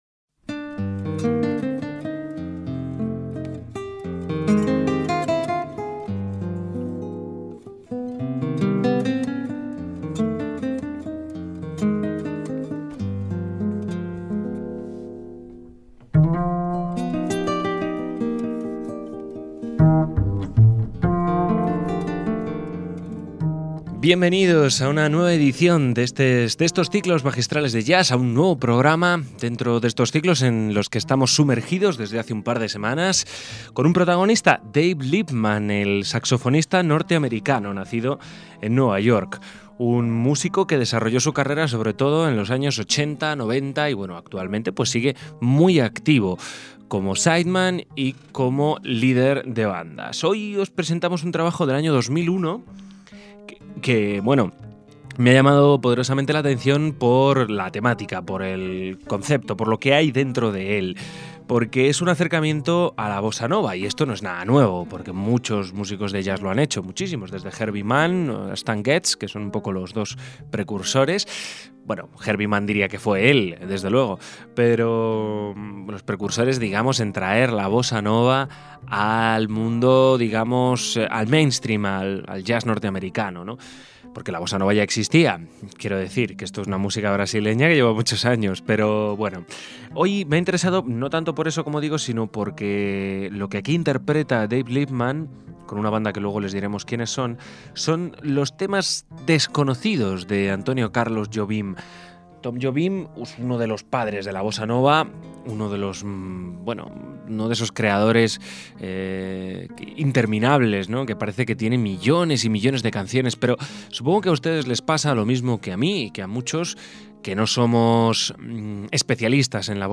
bajo
guitarras
batería y percusiones